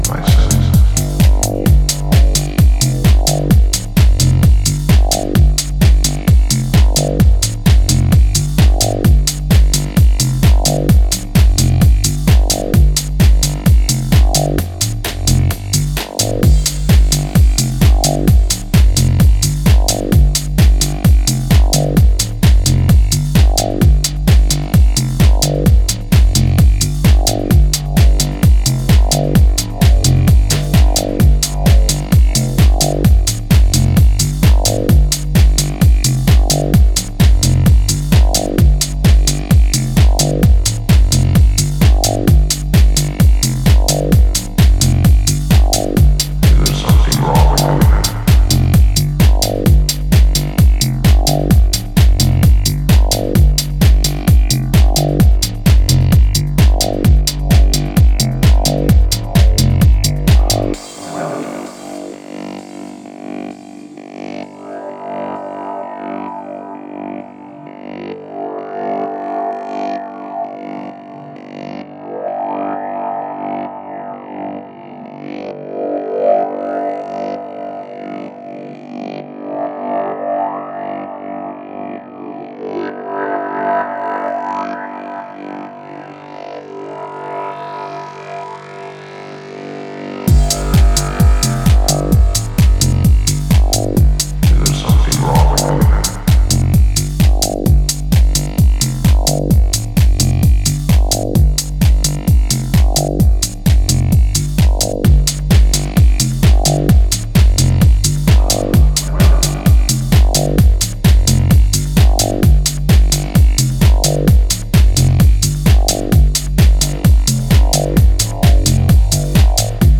club tools